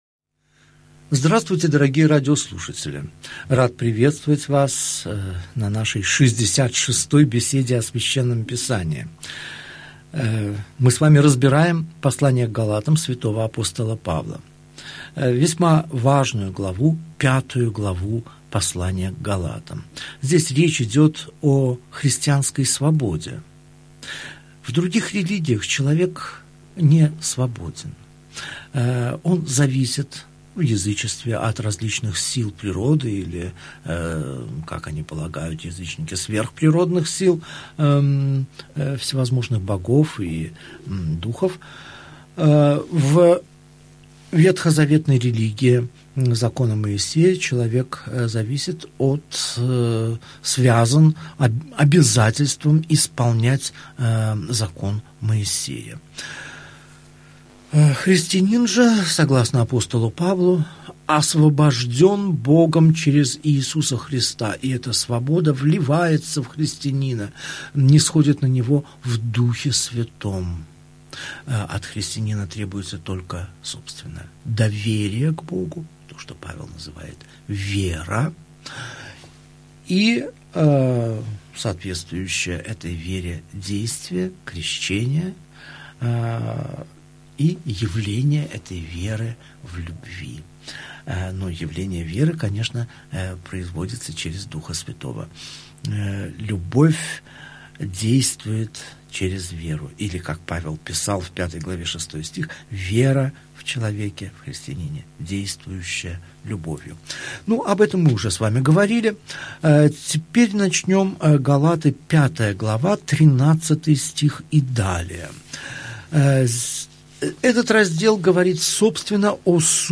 Аудиокнига Беседа 66. Послание к Галатам. Глава 5, стихи 12 – 24 | Библиотека аудиокниг